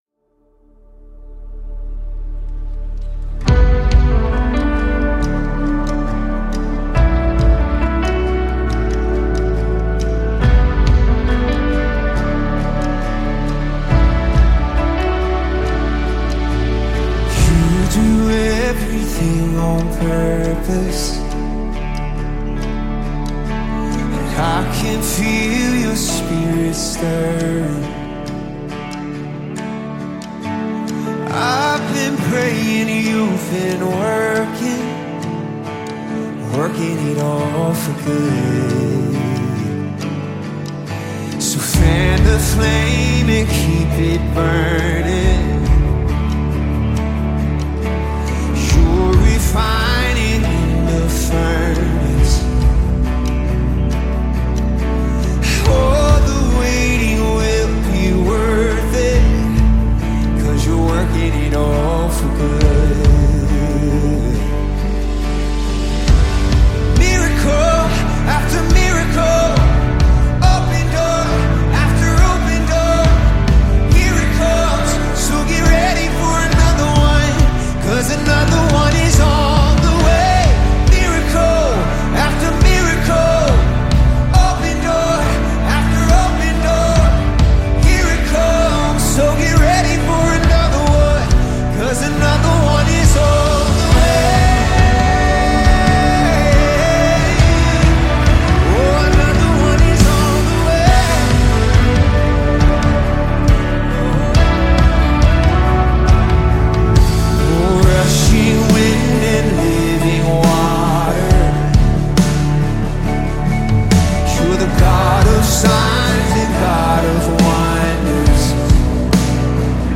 276 просмотров 342 прослушивания 13 скачиваний BPM: 69